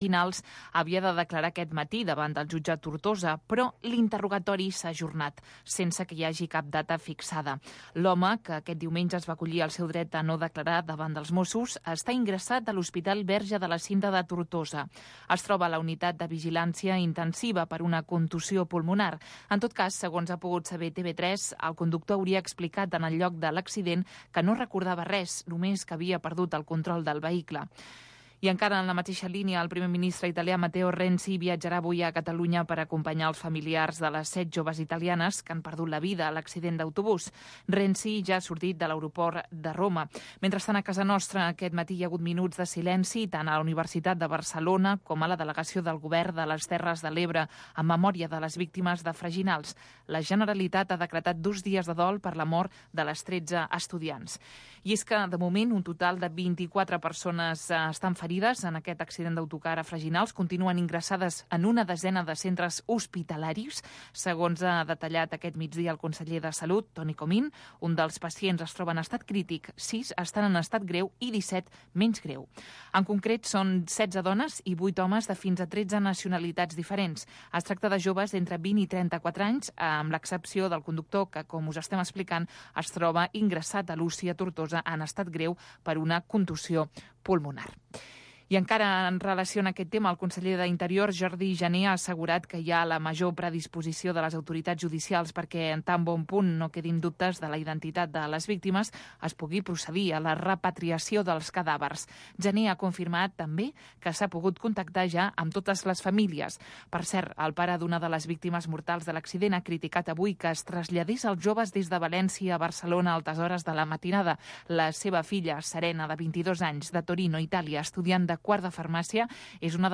Estem amb tu. Magazín cultural de tarda.